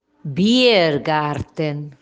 Pronúncia: Bíer-gartem